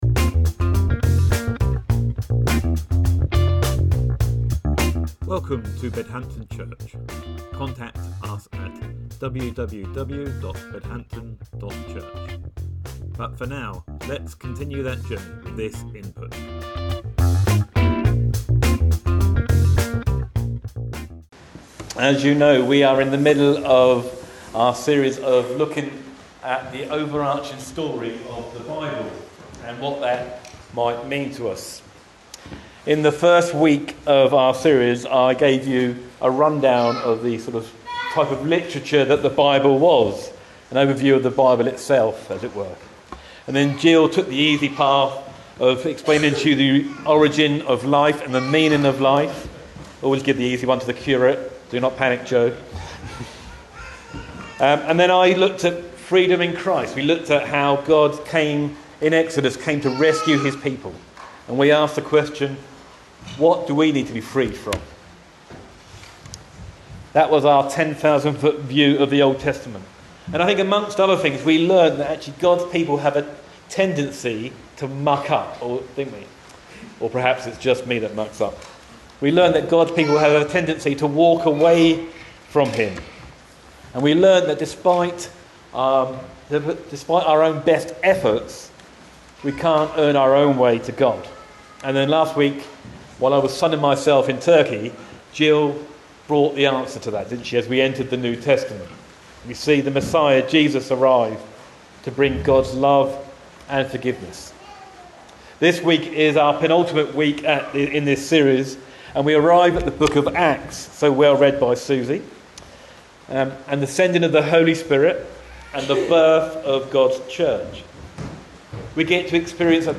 Sermon October 29th, 2023 – Experience a better story: Spirit and Community